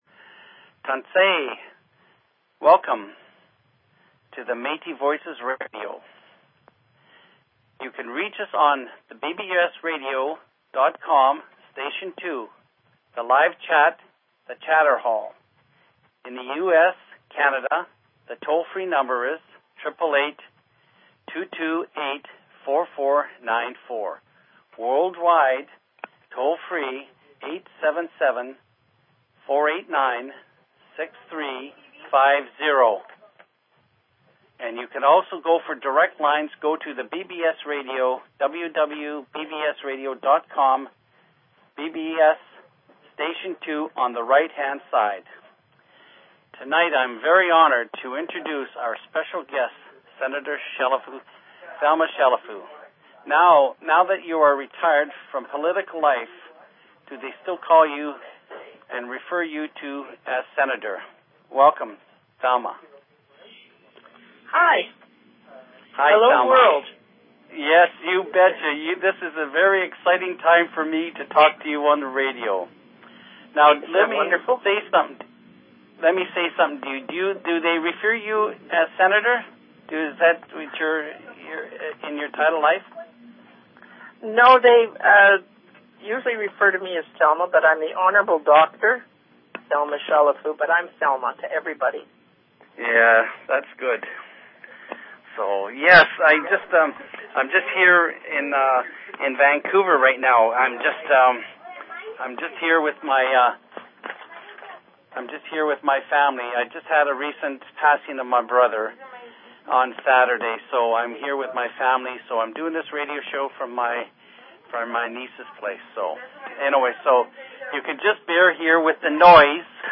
Talk Show Episode, Audio Podcast, Metis_Voices_Radio and Courtesy of BBS Radio on , show guests , about , categorized as